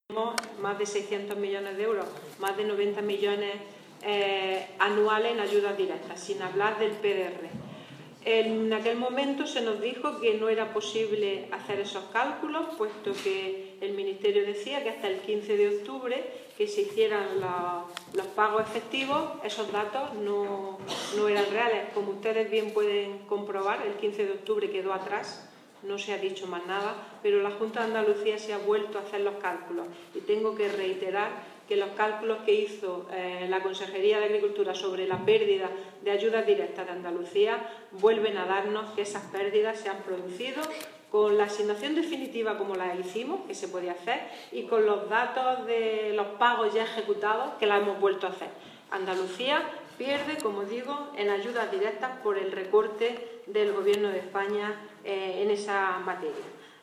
Declaraciones de Carmen Ortiz sobre adelanto de ayudas de la PAC